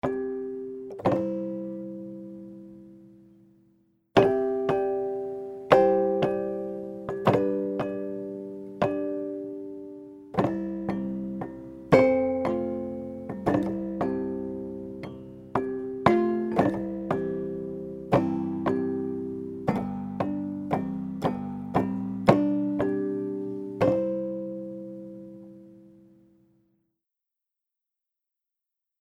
Neben dem Direktklang wurde auch die reine Mechanik per Mikofon aufgenommen. Diese Variante zeichnet sich durch einen außergewöhnlich fragilen Klang aus: